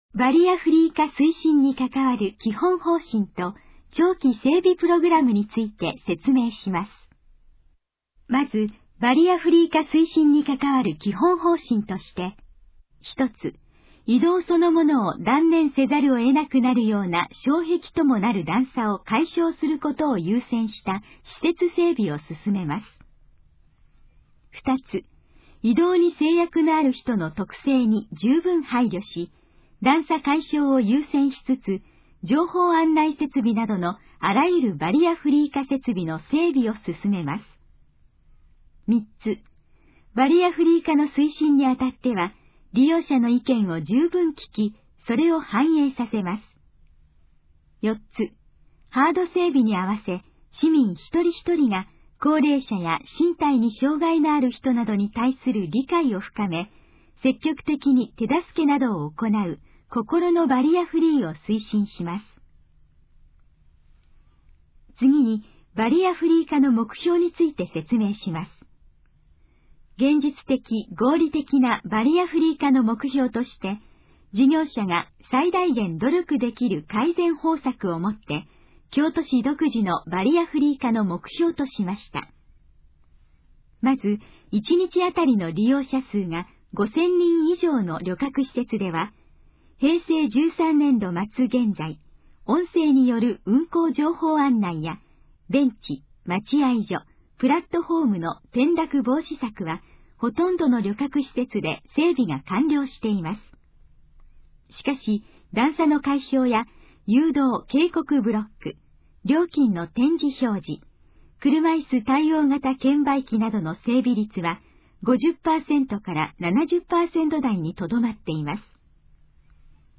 このページの要約を音声で読み上げます。
ナレーション再生 約894KB